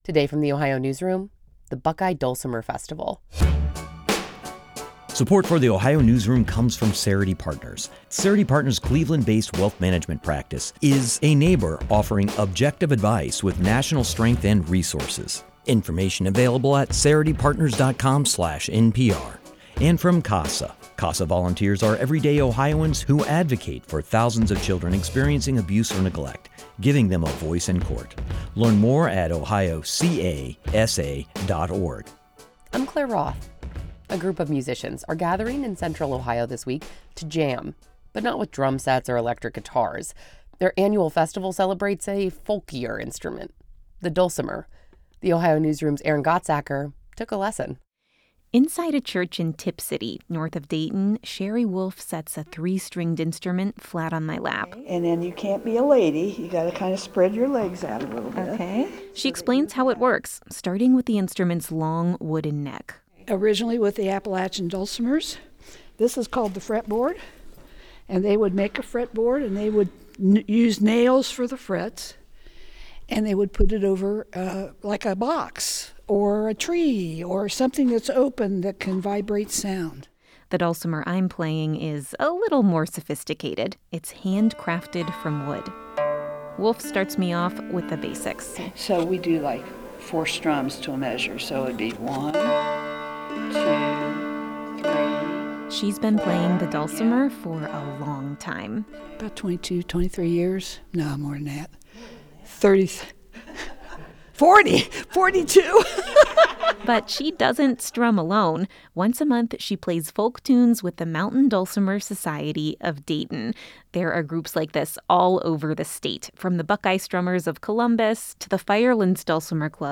A dulcimer lesson
But others play a different instrument entirely: the hammered dulcimer, which they tap with wooden mallets so it rings like a piano.
dulcimers-web.mp3